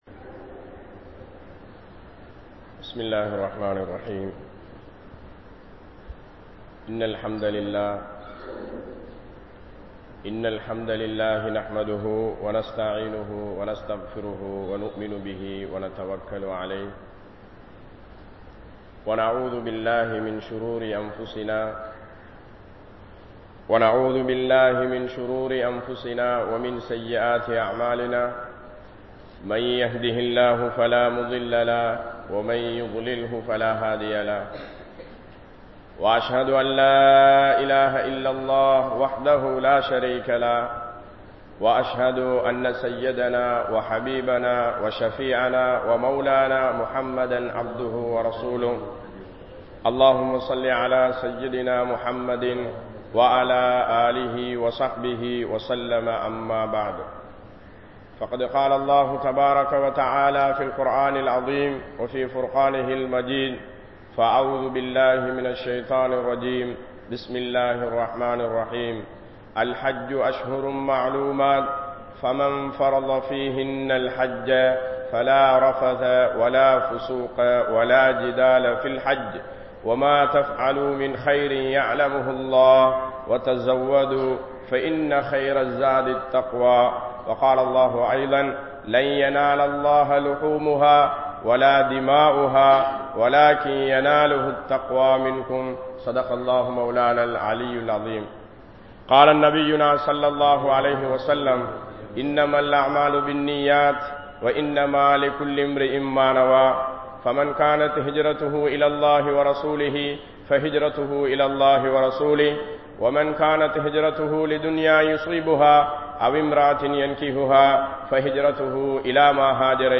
Hajj Koorum Padippinai (ஹஜ் கூறும் படிப்பினை) | Audio Bayans | All Ceylon Muslim Youth Community | Addalaichenai
Ansari Jumua Masjith